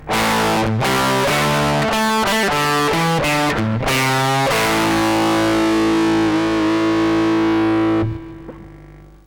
Записано все на советский конденсаторный микрофон (или в линию).
Теперь про драйв: